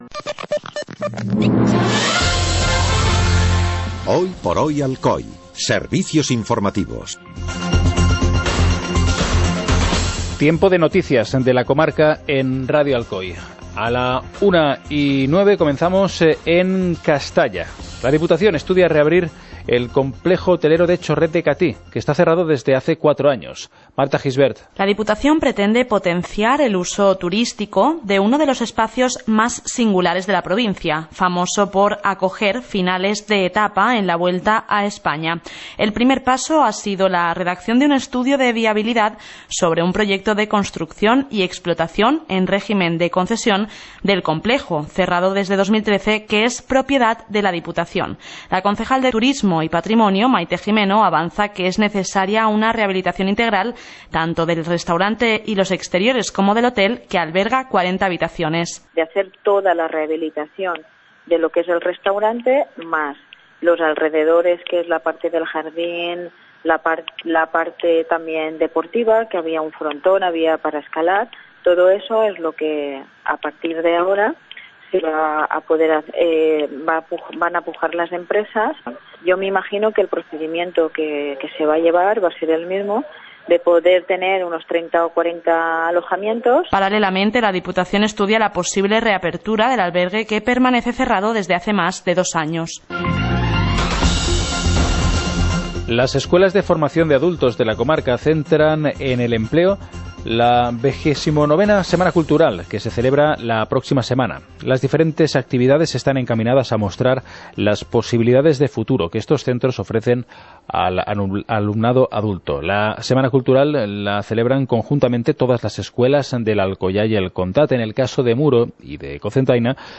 Informativo comarcal - jueves, 16 de febrero de 2017